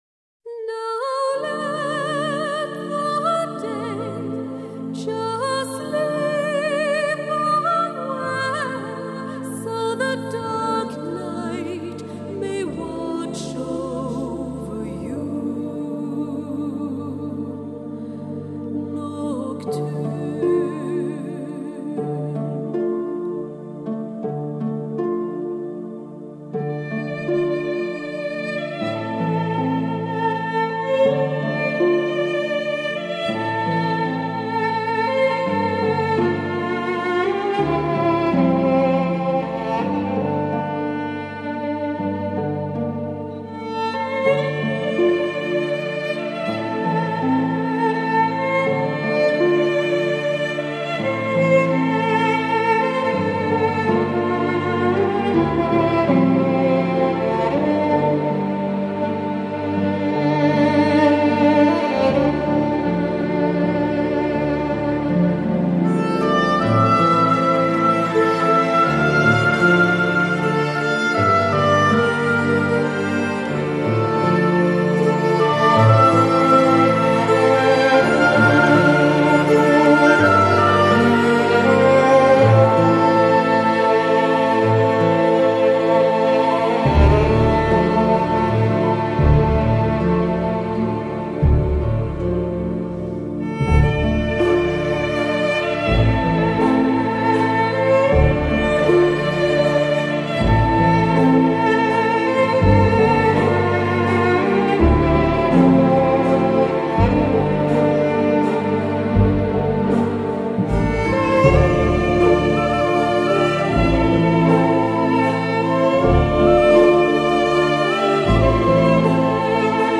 新世纪音乐
这张专辑风格比较统一，乐曲舒缓柔美，充满了思忆与郁忧，不经意之间流露出些许的忧愁，令人不得不沉醉于其中。
Keyfiddle
Whistle